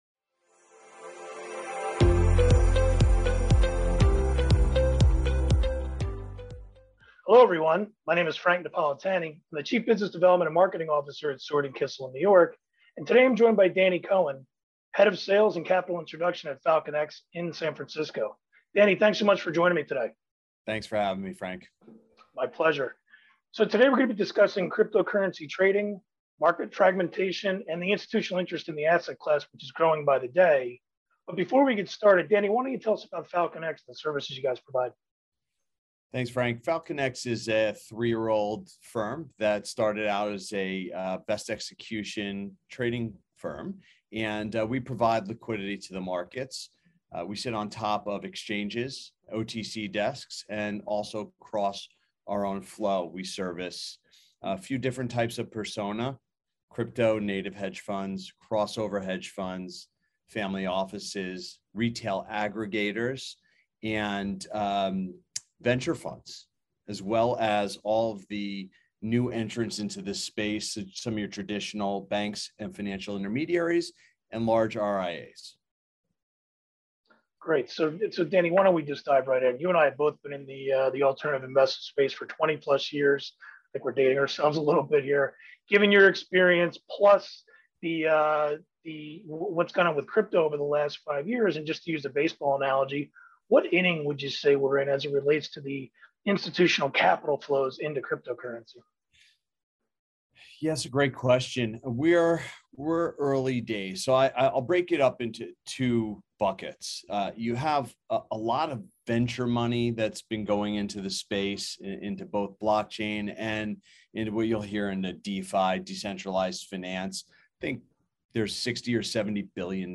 FalconX: An interview with the most advanced digital asset trading platform in the world